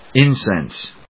/ínsens(米国英語), ˌɪˈnsens(英国英語)/